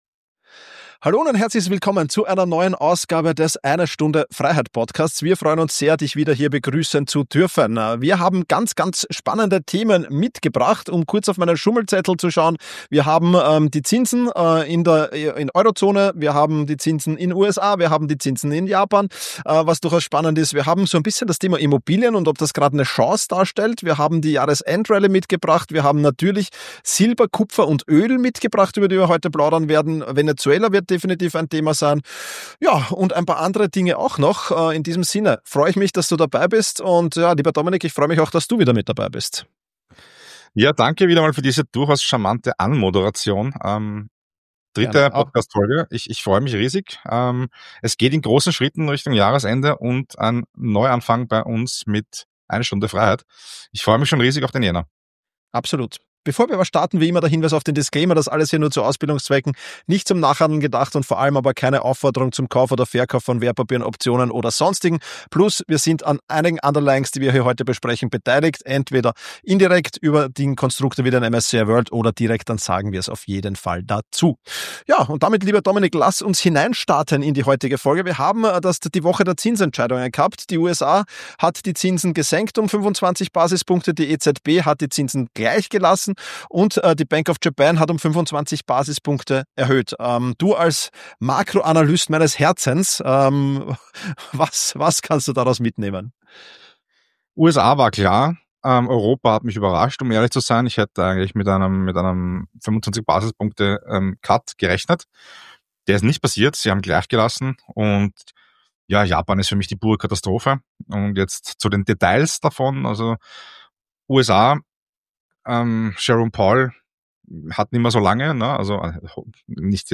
Diesmal analysieren die beiden Hosts die Woche der Zinsentscheidungen und beleuchten, welche Auswirkungen die aktuellen Leitzinsänderungen in der Eurozone, den USA und Japan haben.